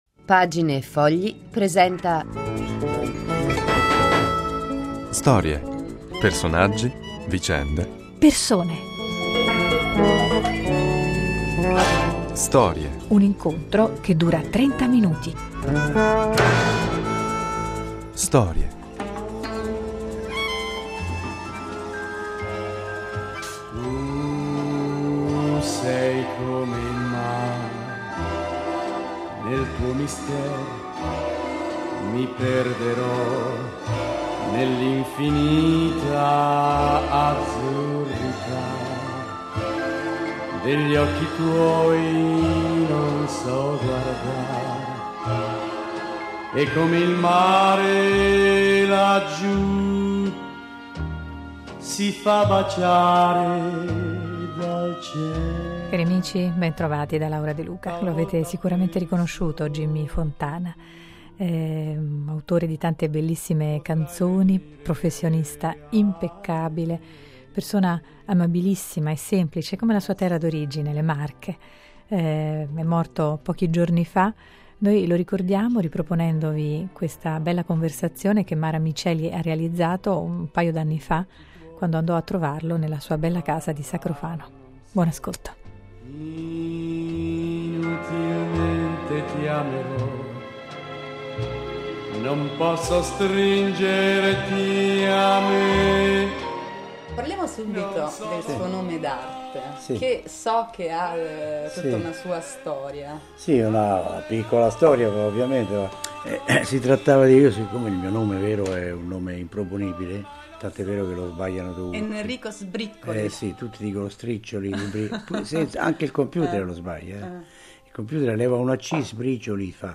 Una conversazione informale in una grande cucina , sorseggiando un caffè preparato per l'occasione.